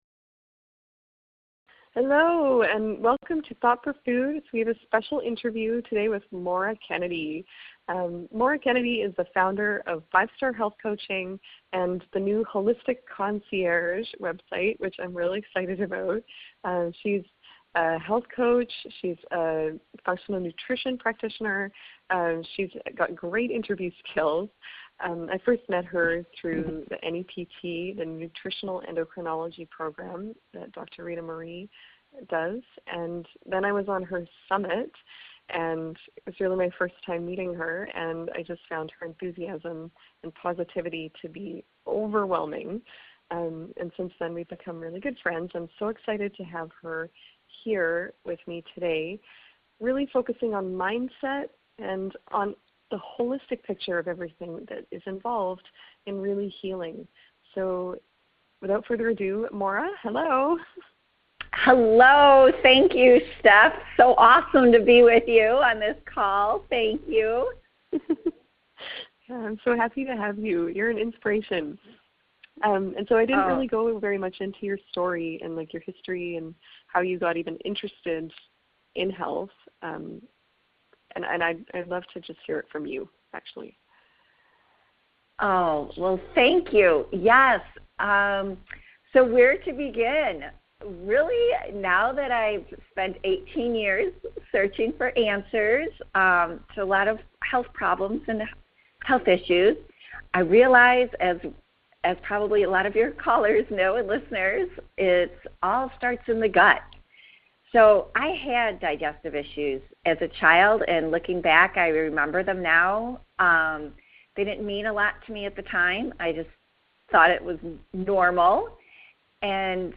You will find two friends talking about the struggles of chronic illness, relationships, recovery and state of mind. You will also find three simple things that you can do every day to help improve your state of mind.